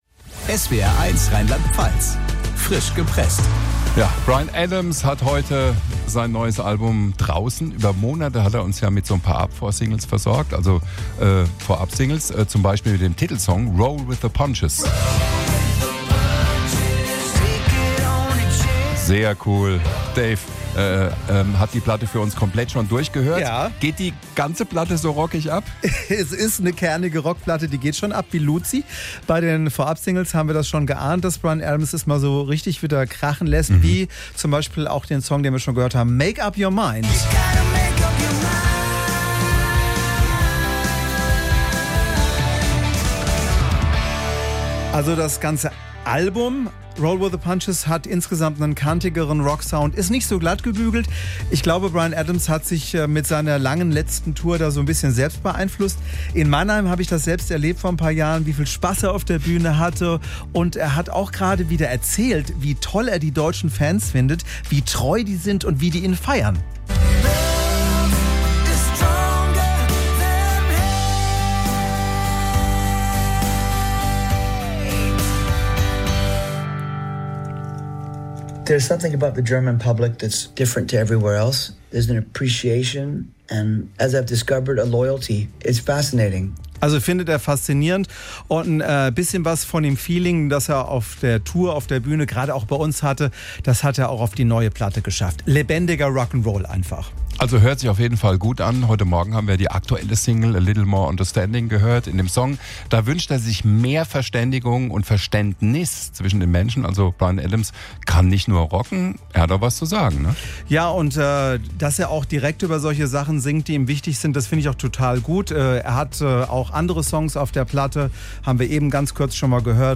Lebendiger Rock
Von Rocksound bis Ballade ist alles dabei.
Es ist eine kernige Rockplatte.